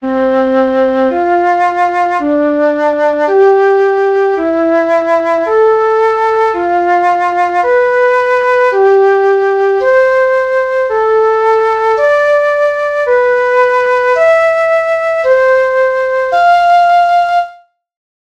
• Agora, practica estes invervalos de cuarta, tamén a modiño intentando afinar ben: